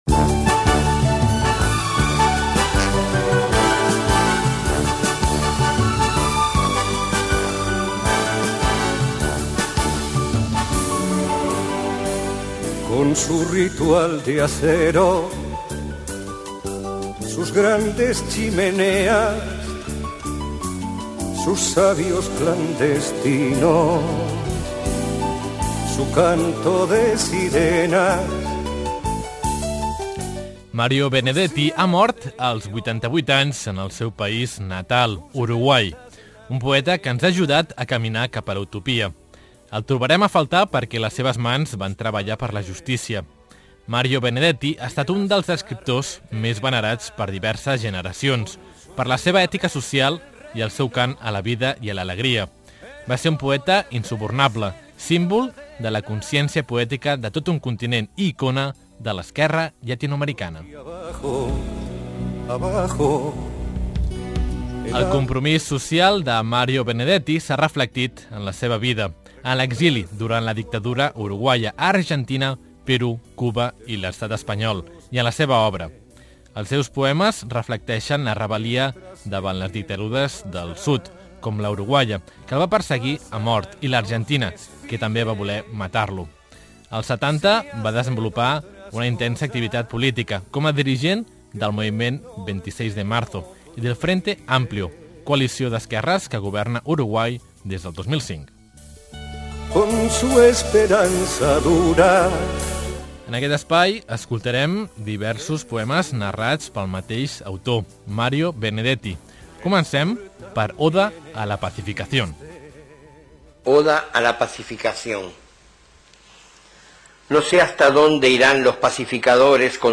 REPORTATGE sobre Mario Benedetti